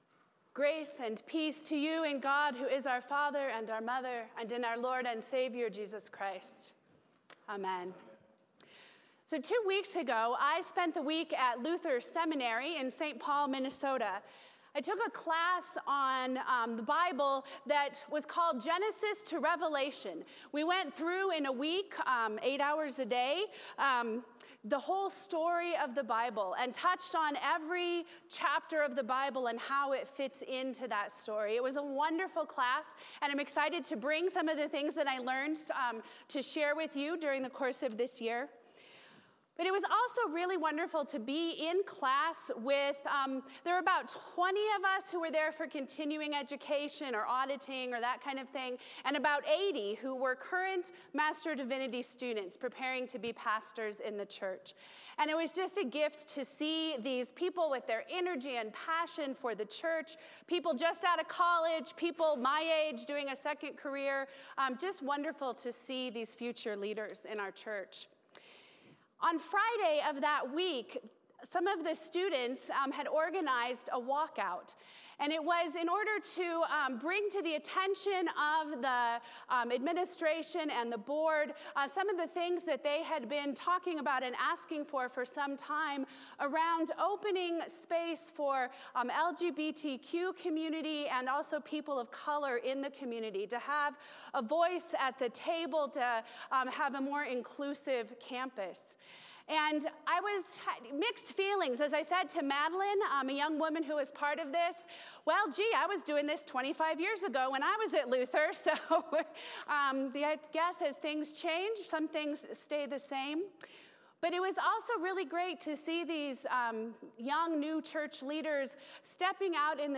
Passage: Mark 1:21-45 Service Type: Sunday Morning